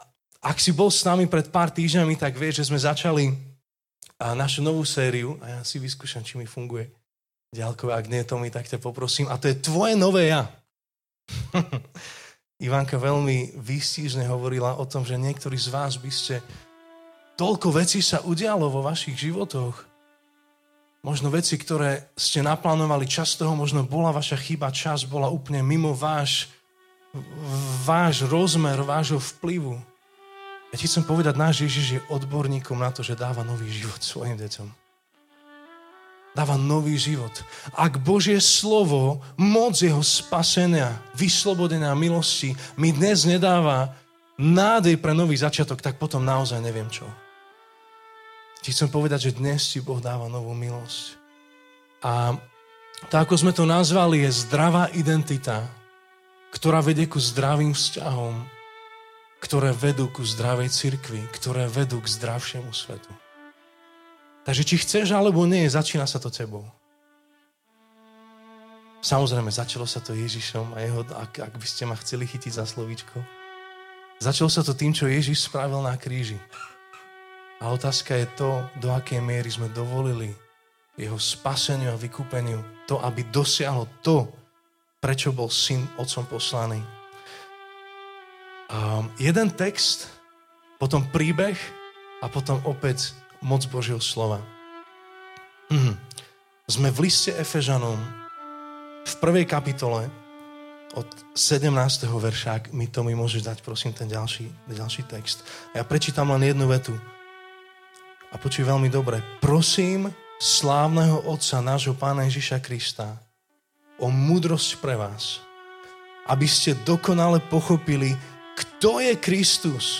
2.časť zo série vyučovaní z listu Efežanom